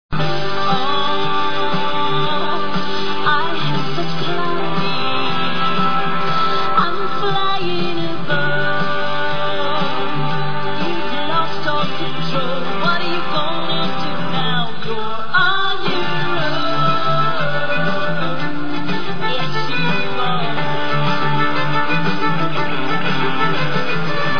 (Live theme)